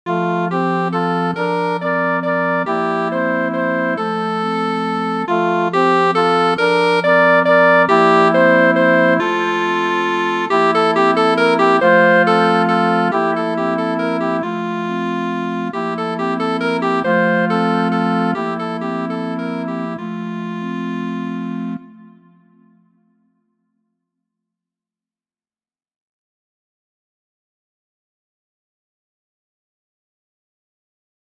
Sixteenth rhythms and tempo changes for bird sounds.
• Key: C Major
• Time: 3/8
• Musical Elements: notes: dotted quarter, eighth, sixteenth; tempo: allegro, poco rit. (ritardando); dynamics: piano/soft, mezzo forte/medium loud, crescendo, decrescendo